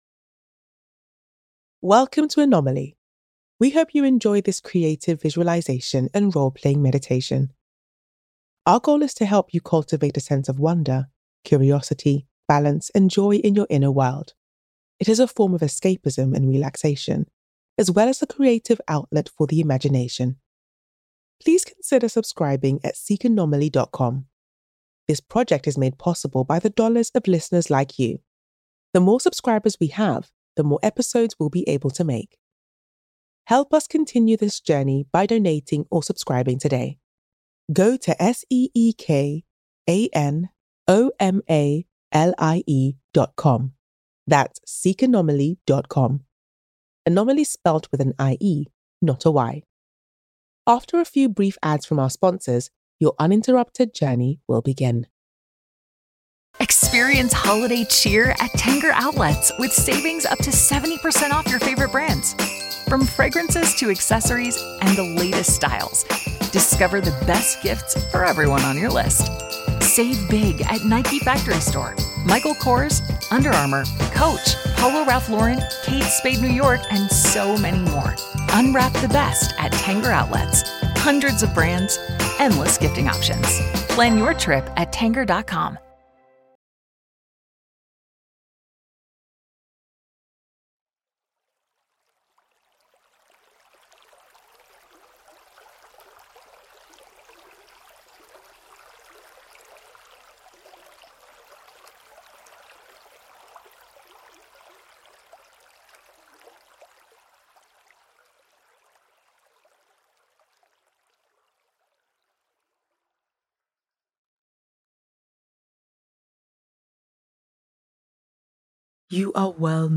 Anomalie (role-playing visualization)